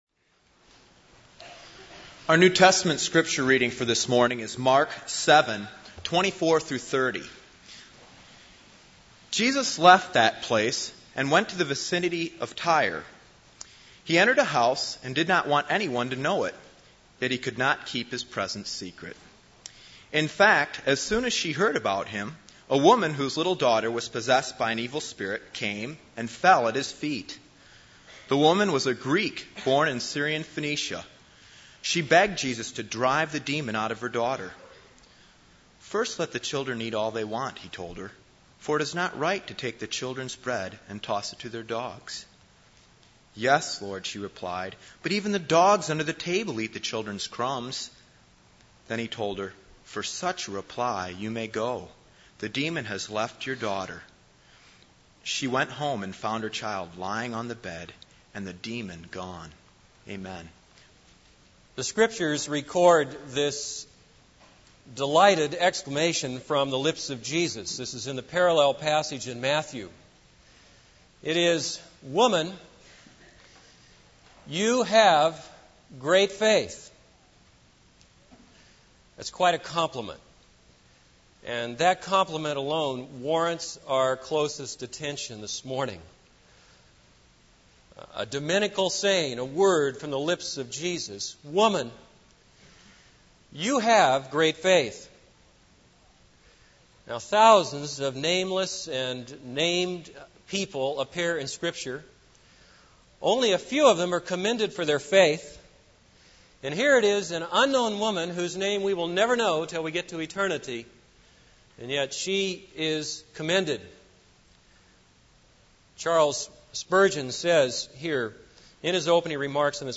This is a sermon on Mark 7:24-30.